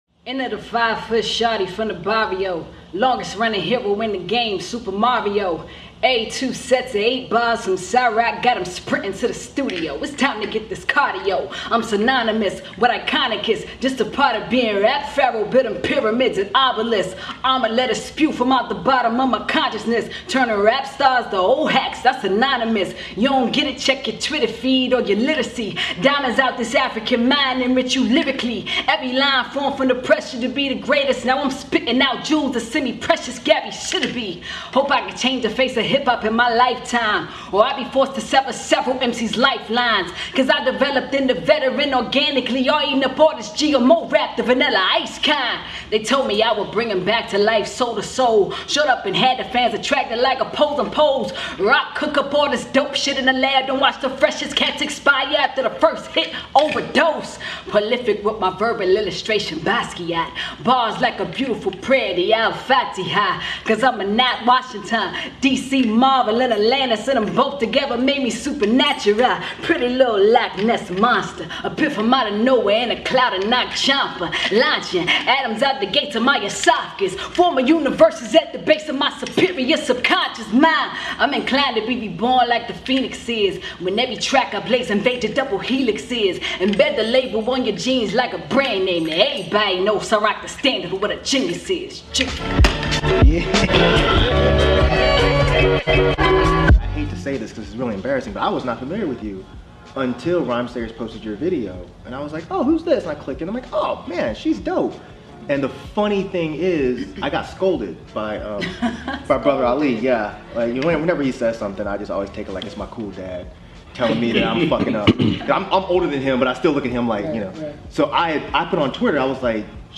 DEHH Interview